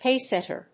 /ˈpeˌsɛtɝ(米国英語), ˈpeɪˌsetɜ:(英国英語)/
アクセント・音節páce・sètter